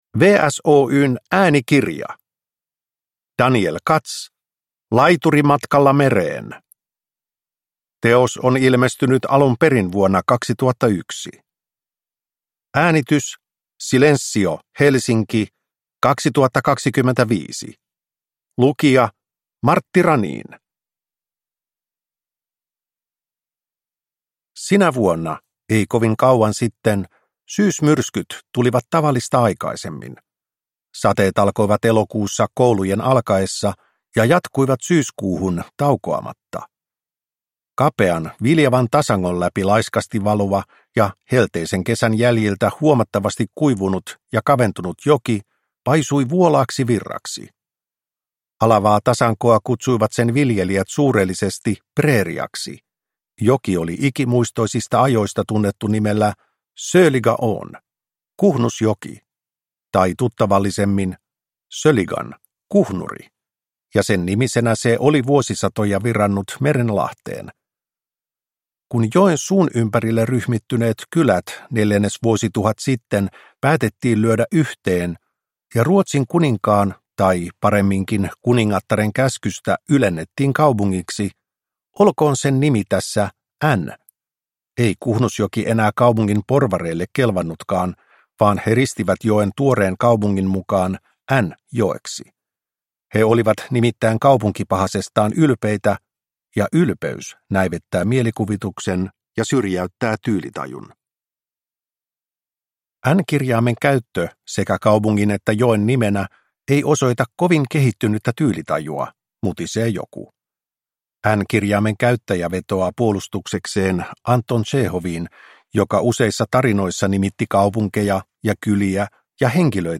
Laituri matkalla mereen – Ljudbok